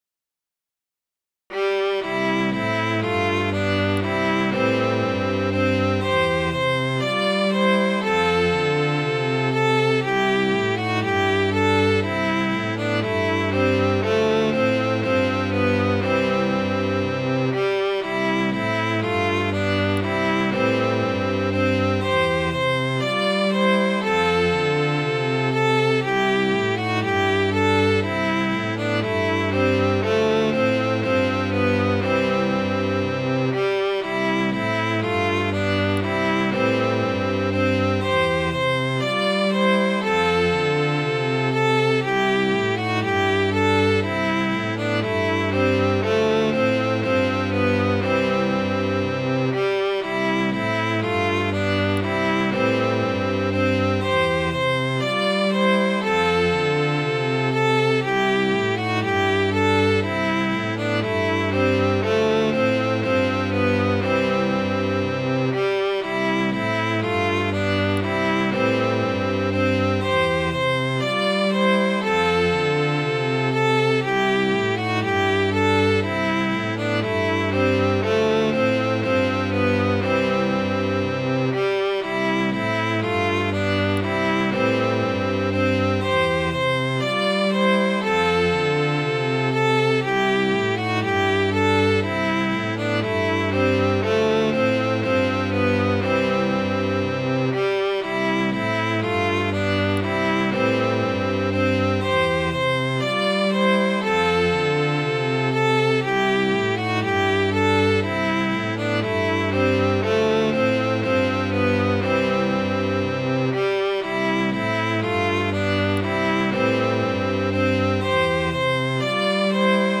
Midi File, Lyrics and Information to The Oxen Song